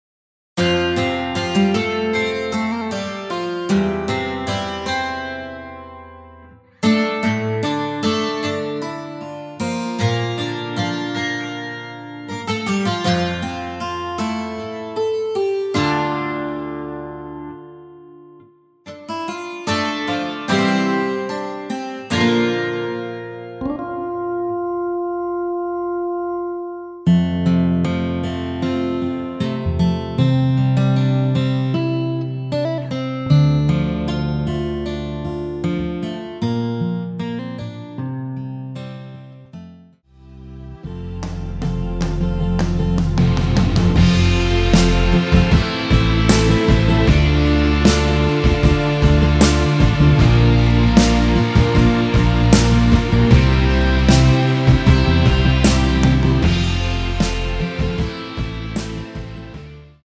-2)내린MR 입니다.
◈ 곡명 옆 (-1)은 반음 내림, (+1)은 반음 올림 입니다.
앞부분30초, 뒷부분30초씩 편집해서 올려 드리고 있습니다.
중간에 음이 끈어지고 다시 나오는 이유는